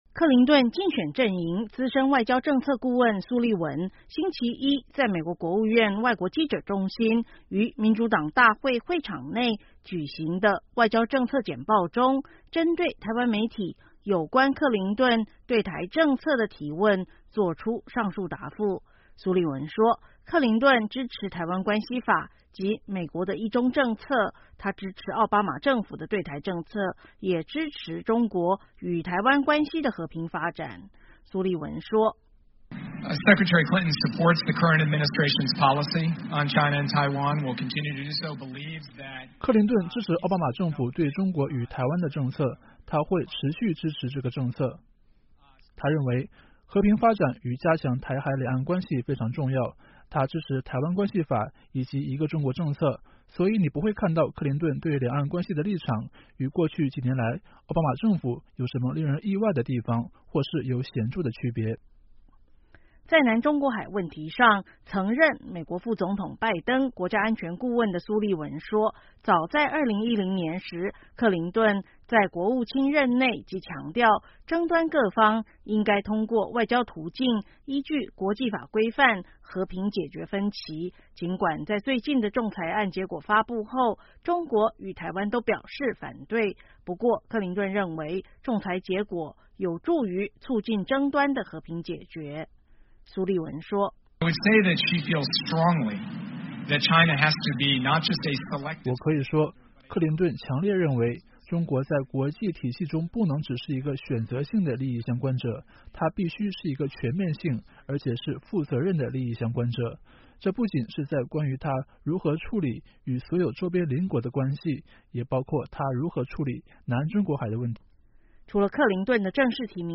克林顿竞选阵营资深外交政策顾问苏利文(Jake Sullivan)，星期一在美国国务院外国记者中心于民主党大会会场内举行的外交政策简报中，针对台湾媒体有关克林顿对台政策的提问做出上述答复。